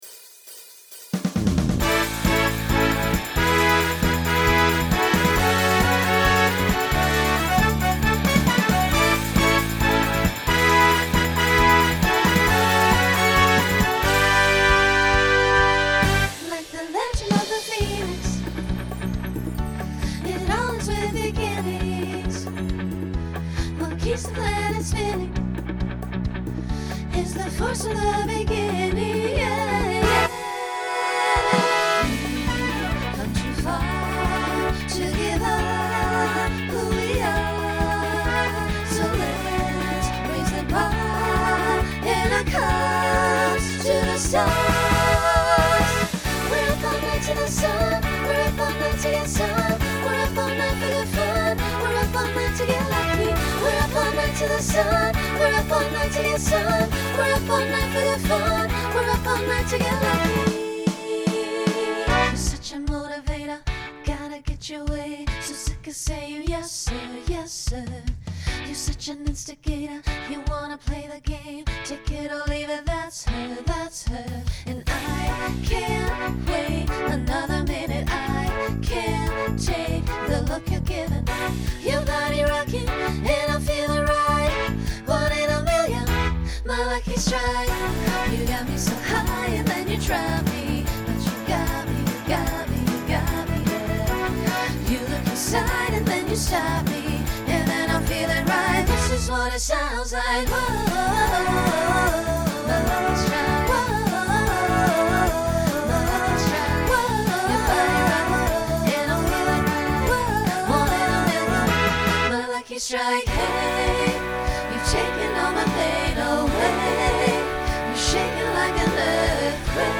Pop/Dance , Rock
Voicing SSA